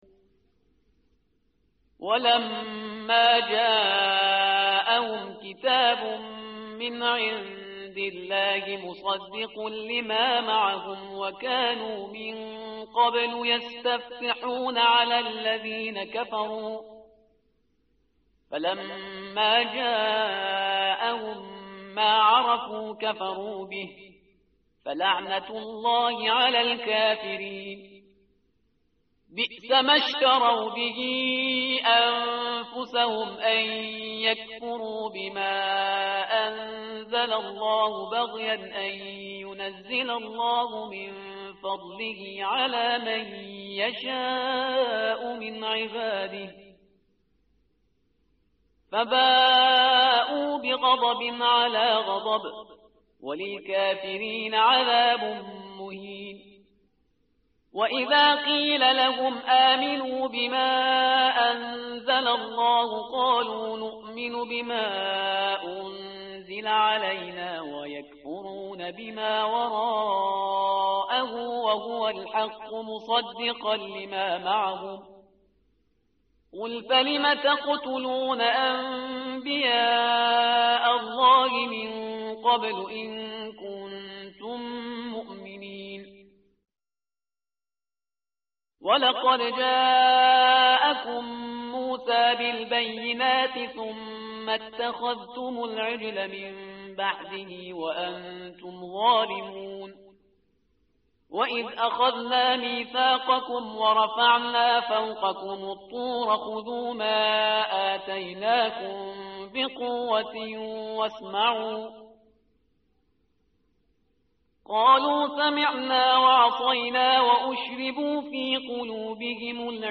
متن قرآن همراه باتلاوت قرآن و ترجمه
tartil_parhizgar_page_014.mp3